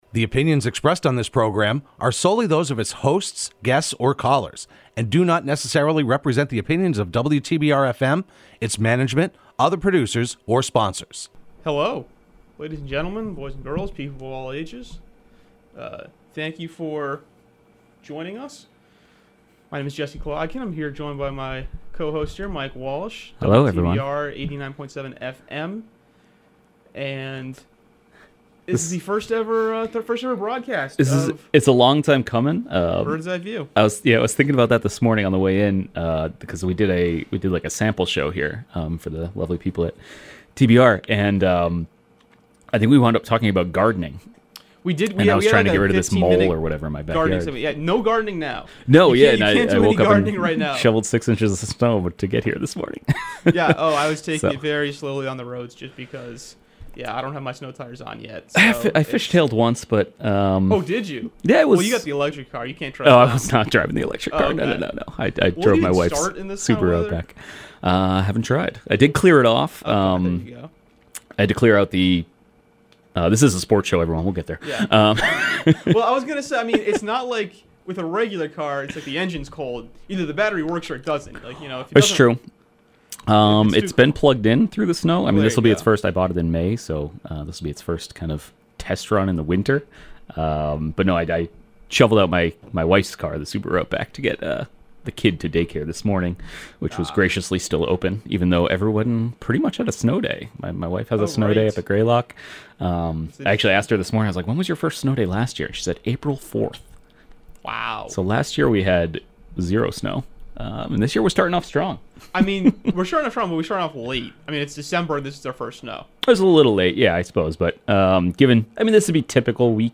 Hosted by the Berkshire Eagle Sports team and broadcast live every Thursday morning at 10am on WTBR.